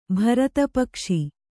♪ bharata pakṣi